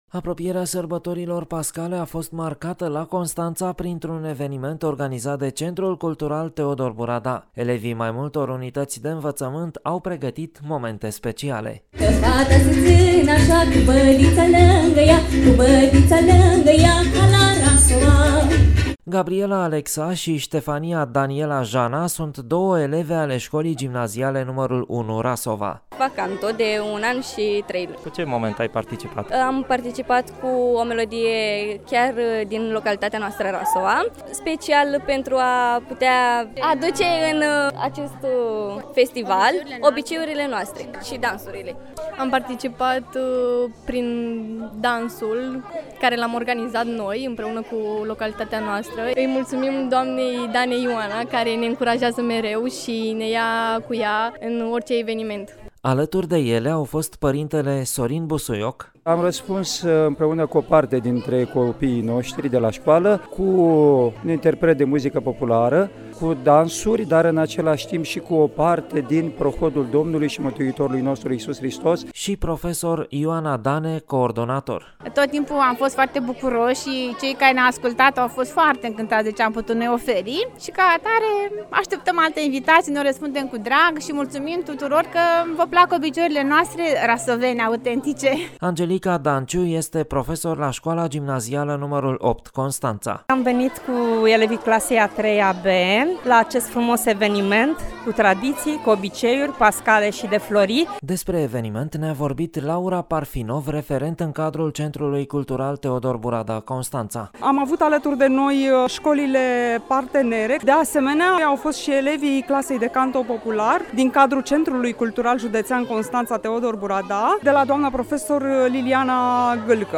Apropierea sărbătorilor pascale a fost marcată la Constanța printr-un eveniment organizat de Centrul Cultural „Teodor T. Burada”.